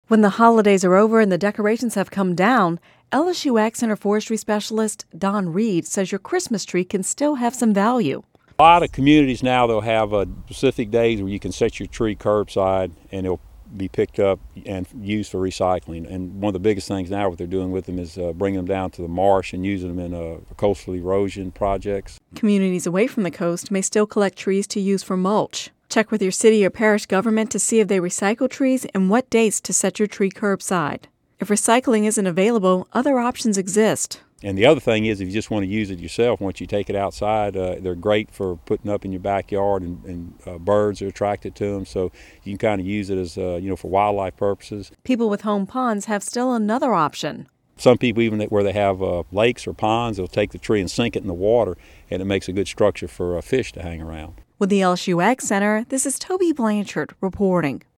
(Radio News 01/03/11) When the holidays are over and the decorations have come down